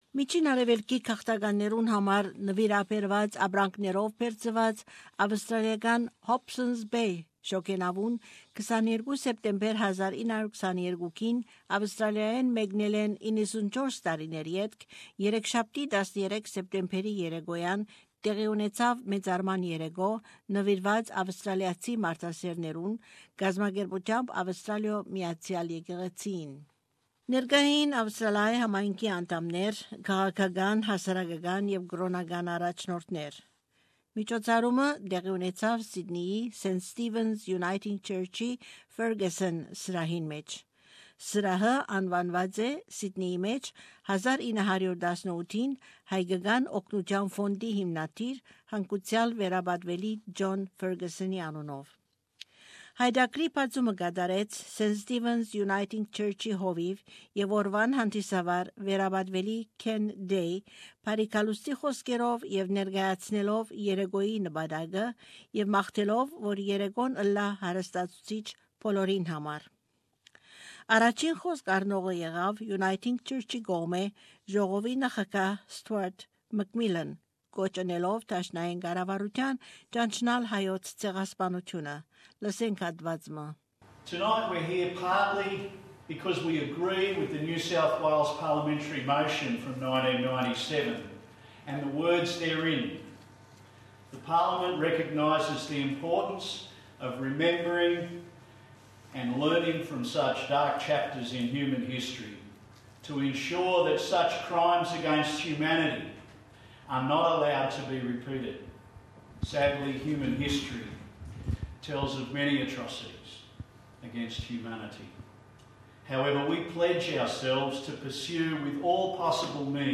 On Tuesday 13 September an evening was held in tribute to Australian humanitarians, 94 years after the Hobsons Bay departed Australian shores laden with donated goods for Armenian refugees in the Middle East. These are excerpts from that evening's program.